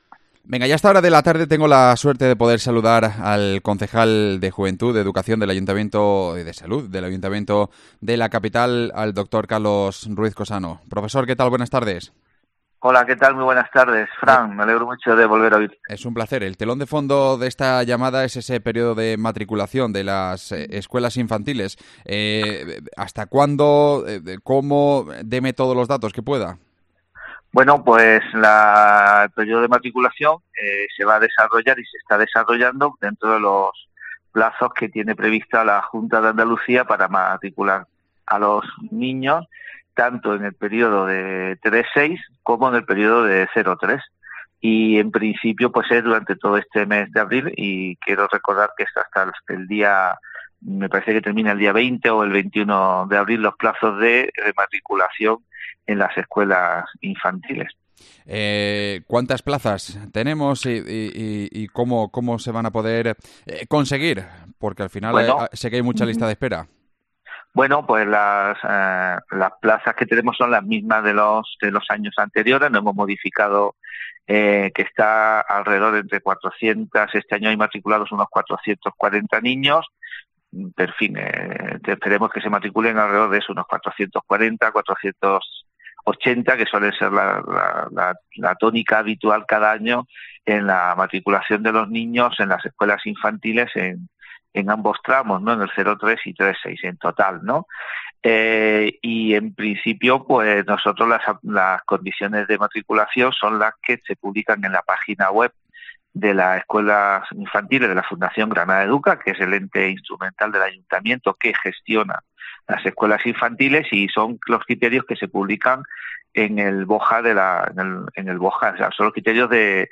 AUDIO: Hablamos con el concejal de educación Carlos Ruiz Cosano